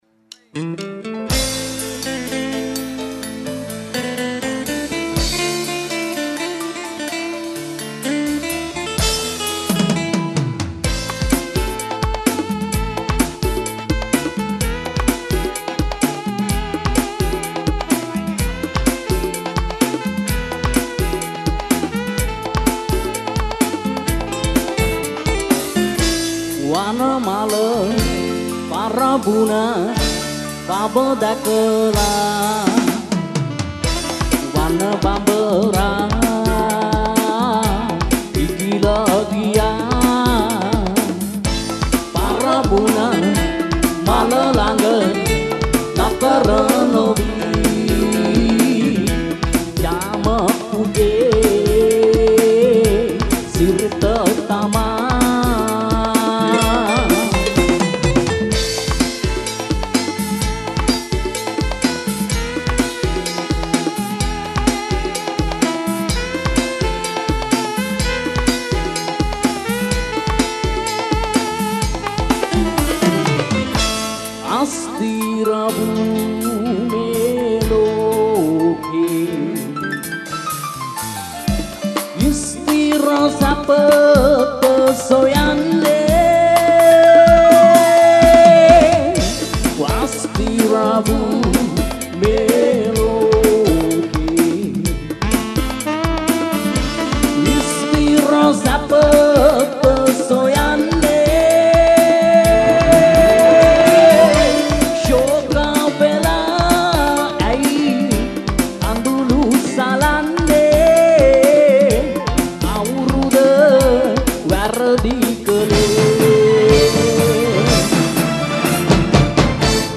Srilanka No.1 Live Show Download Site...